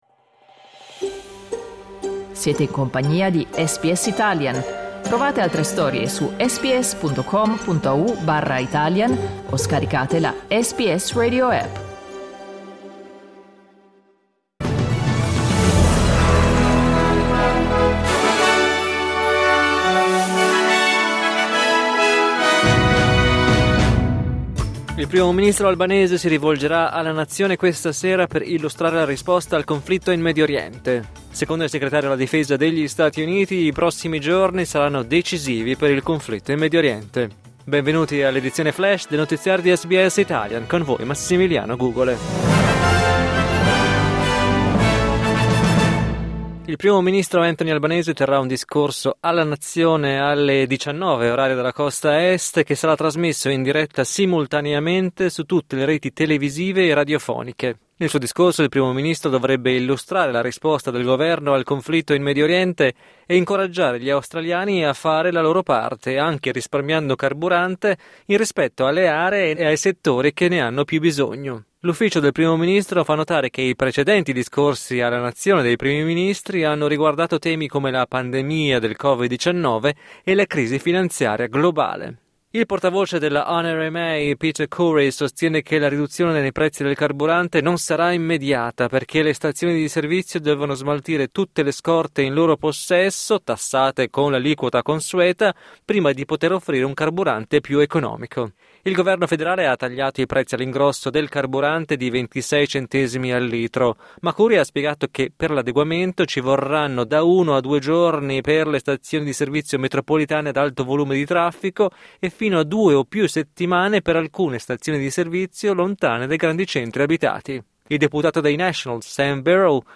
L’aggiornamento delle notizie di SBS Italian.
News Flash